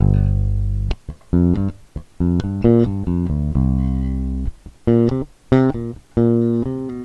Denoiser is an efficient noise removal plugin that removes the background noise (hiss) from a track, by learning the characteristics of the noise.
denoiser-before.mp3